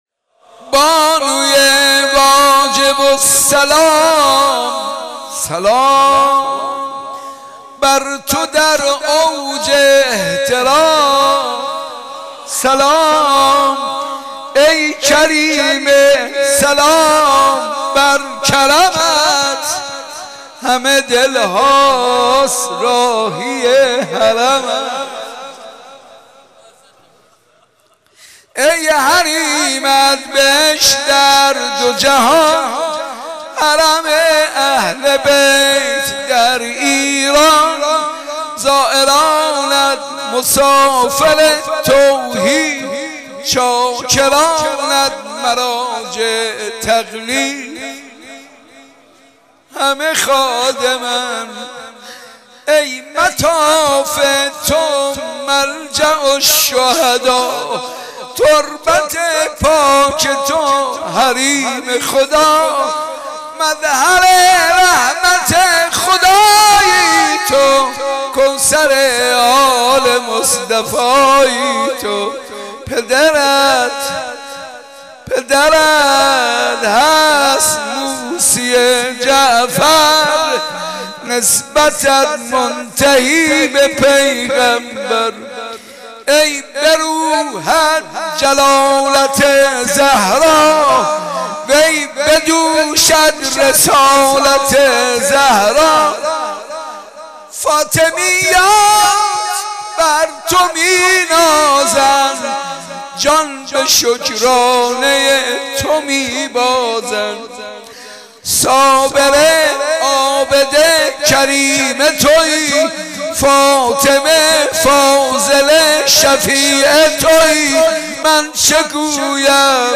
برچسب ها: شهدای ایران ، صوت ، مداحی ، حاج منصور ، حضرت معصومه ، شهید ، شهدا ، جانبازان ، ایثارگران ، ایران شهید